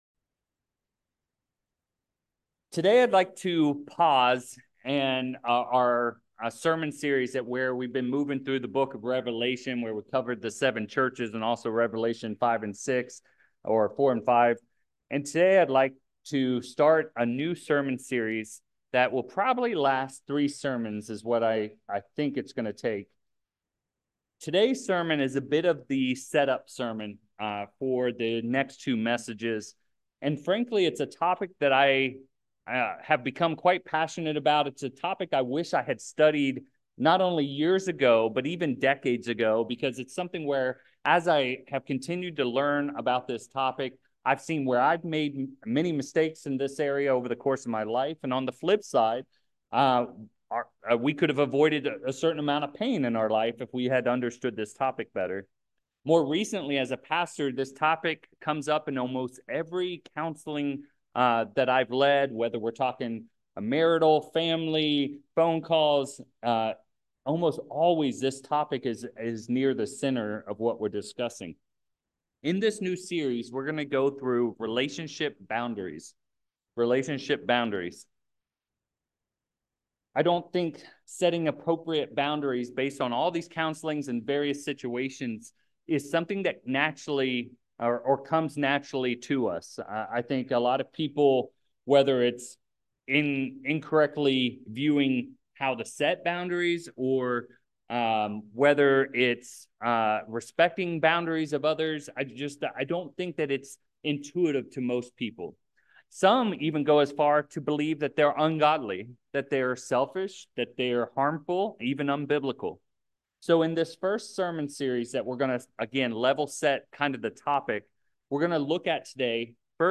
2/24/24 This is a new sermon series designed to dive into the topic of setting boundaries in our many relationships. In this first sermon of the series, we look at how God is a relationship boundary setter. We will also see that God enforced consequences when His communicated boundaries were broken.